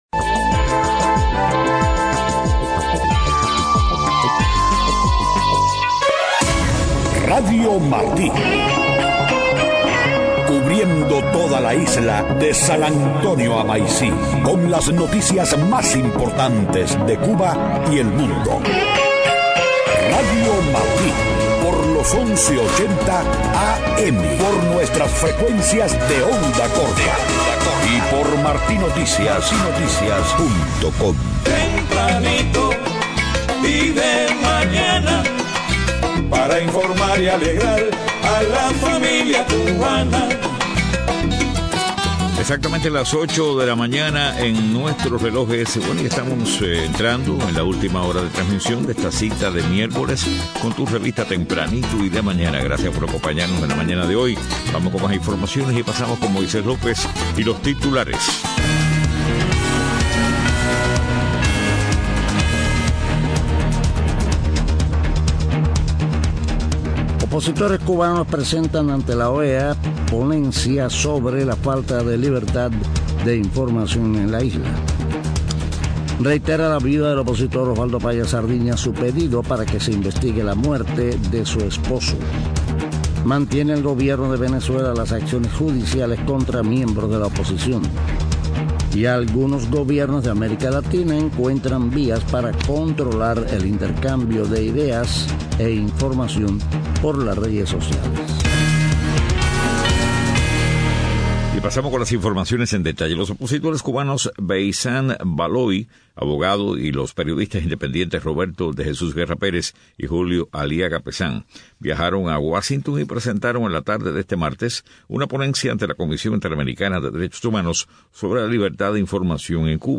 8:00 a.m. Noticias: Opositores y periodistas denuncian en la OEA falta de libertad e información en Cuba.